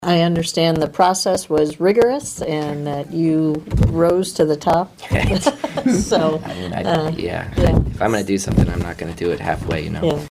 The third Supervisor, Carol Hibbs was also glad to have a full board again.